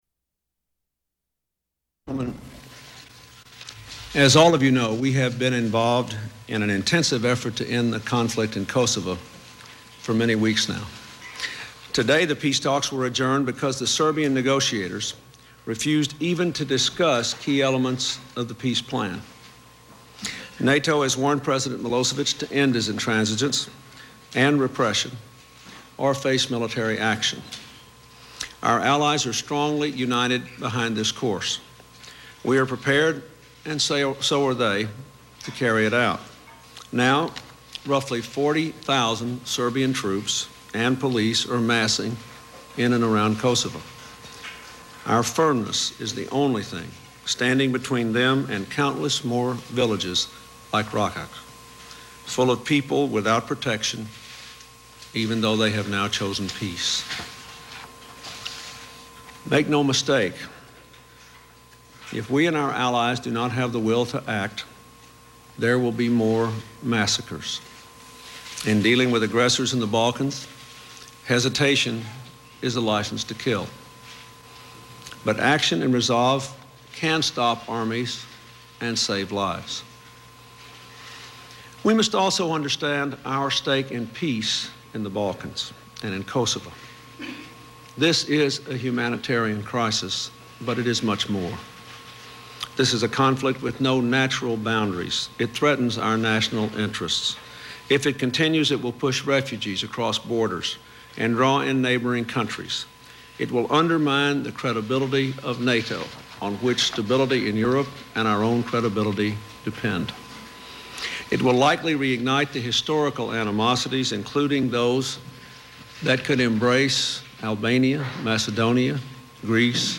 President Bill Clinton hosts his first news conference in 11 months. Clinton answers questions about Kosovo, Chinese espionage, Hillary Clinton's political ambitions, the claims of Juanita Broderick, the George Stephanopoulus book, and about telling the truth.
Broadcast on PBS-TV, News Hour, March 19, 1999, 1900 EST.